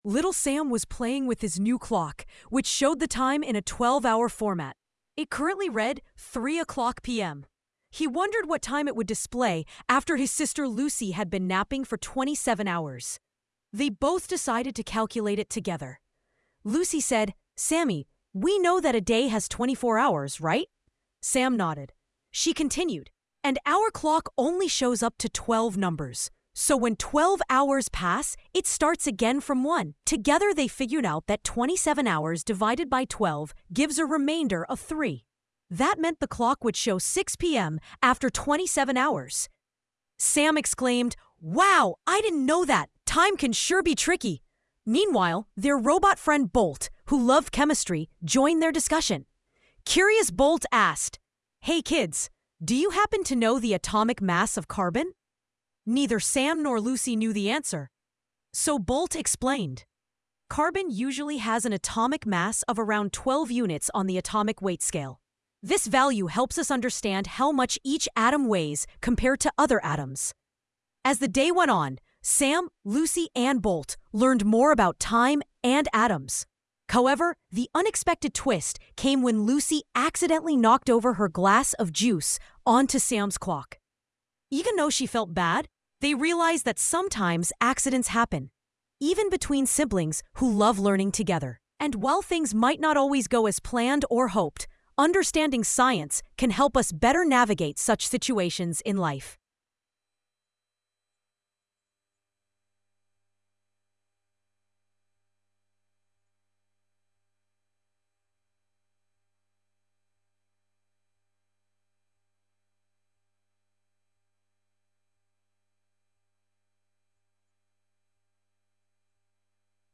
story
tts